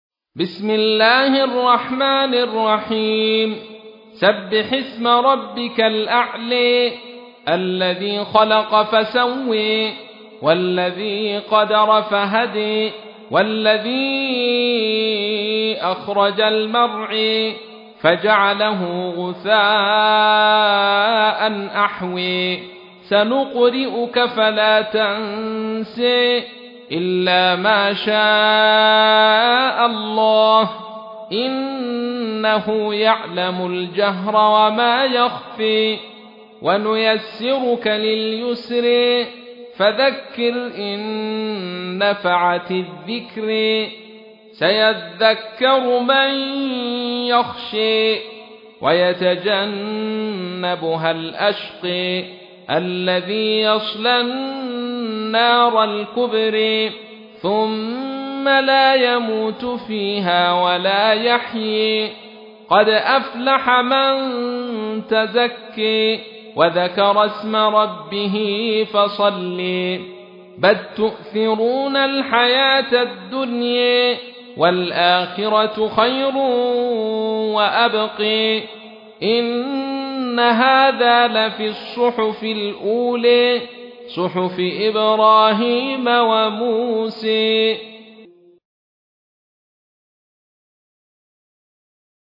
تحميل : 87. سورة الأعلى / القارئ عبد الرشيد صوفي / القرآن الكريم / موقع يا حسين